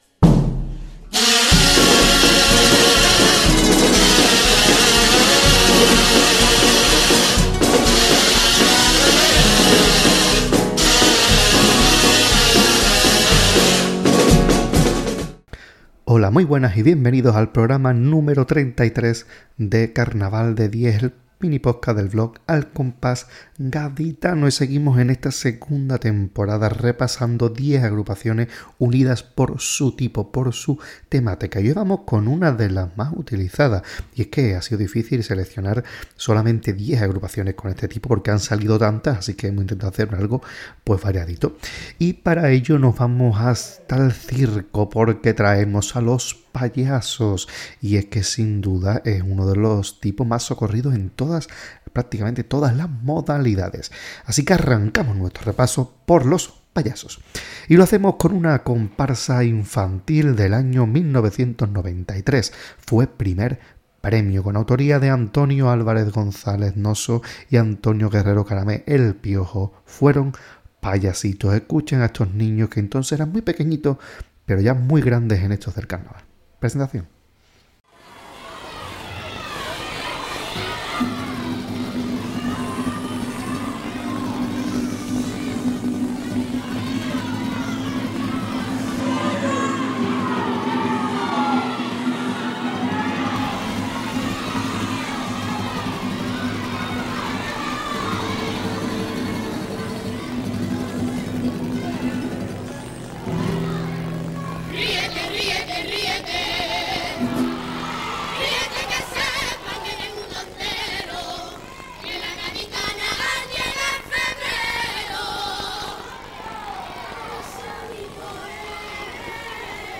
En este séptimo programa de Carnaval de diez en su segunda temporada vamos a ponernos firmes ya que vamos a irnos hasta los cuarteles para traer diez agrupaciones tipos que tienen reminiscencias militares. Traemos coros, comparsas, chirigotas, cuarteto y romancero. Un programa completito para ver diferentes versiones del mundo militar.